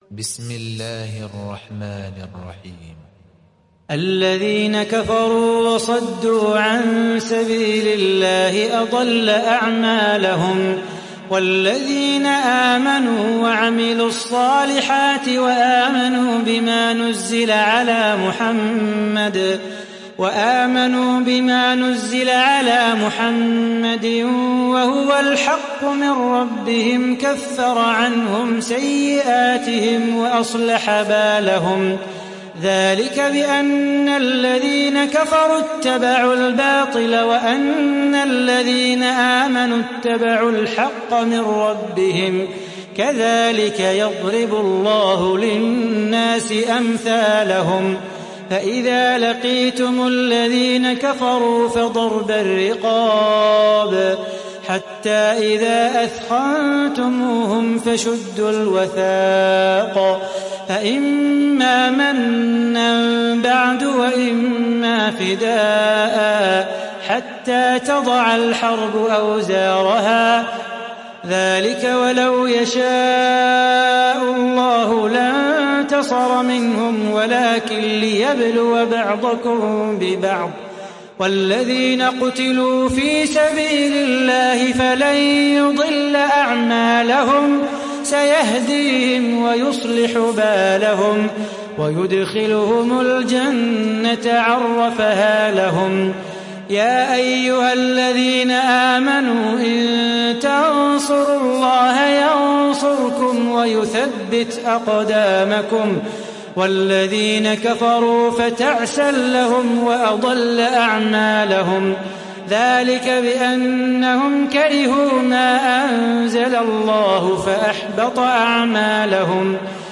تحميل سورة محمد mp3 بصوت صلاح بو خاطر برواية حفص عن عاصم, تحميل استماع القرآن الكريم على الجوال mp3 كاملا بروابط مباشرة وسريعة